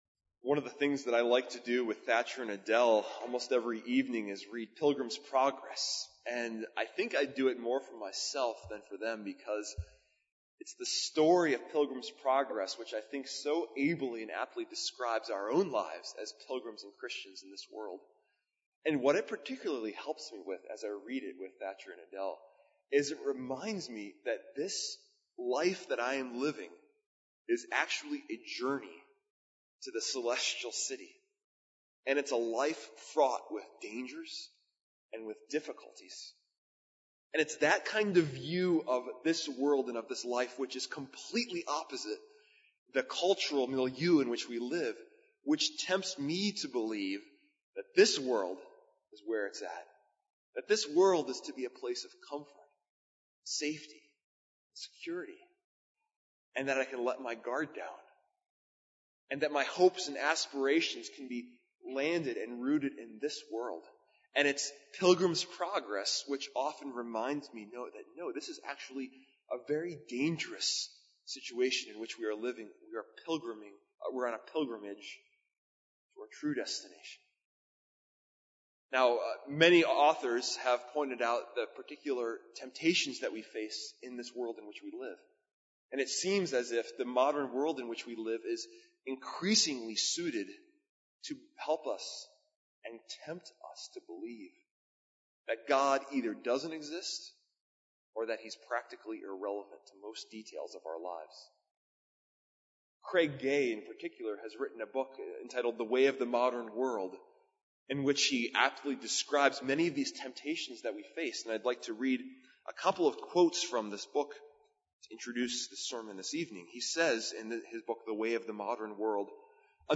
Series: Guest Preacher
Service Type: Sunday Evening